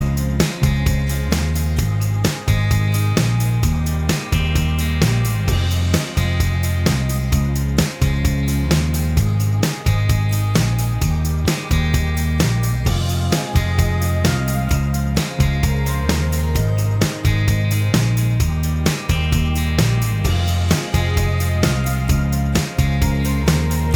Minus Main Guitar Pop (2000s) 4:08 Buy £1.50